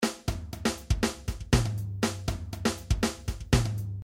描述：嗯，有几个汤姆的循环，自然的鼓声。
Tag: 120 bpm Rock Loops Drum Loops 689.15 KB wav Key : Unknown